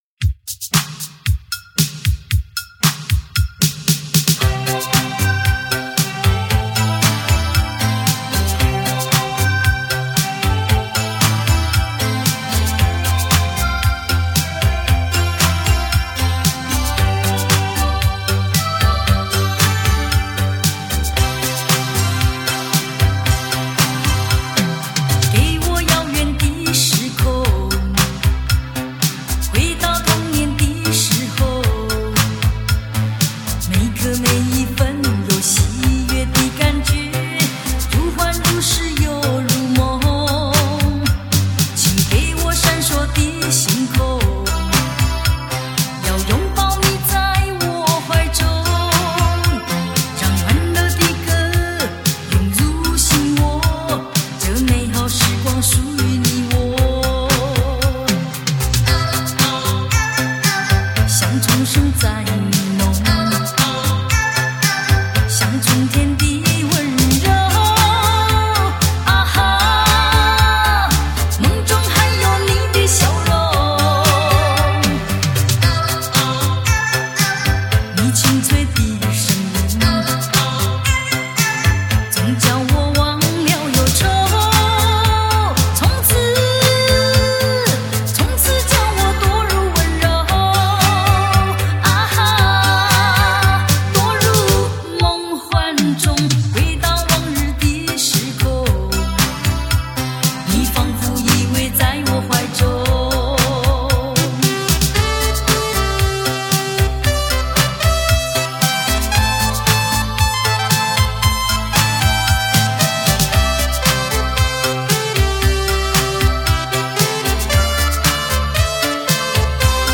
录音室：Oscar Studio S'pore